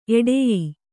♪ eḍeyī